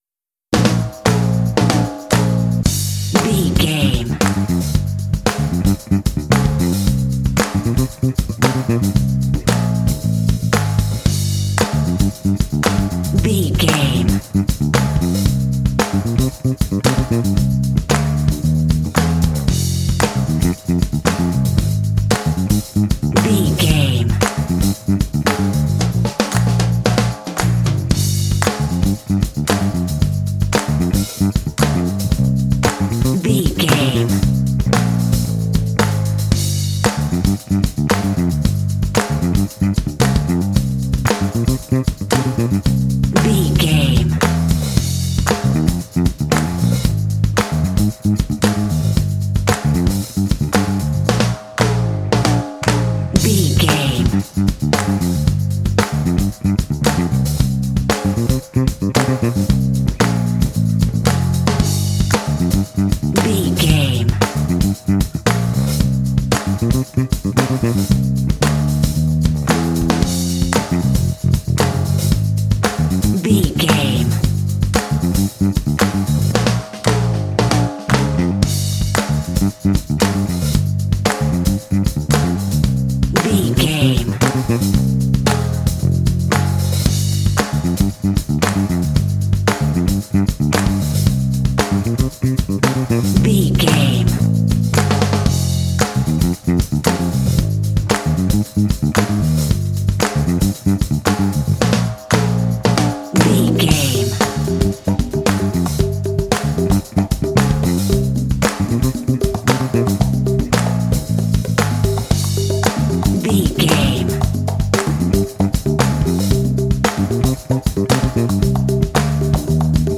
Uplifting
Aeolian/Minor
funky
groovy
bright
lively
energetic
bass guitar
drums
percussion
Funk
jazz